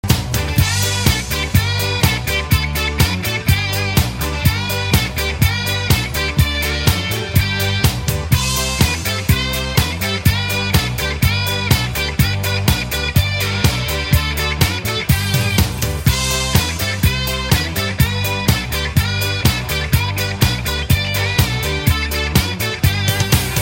без слов
русский рок
новогодние
Позитивная праздничная мелодия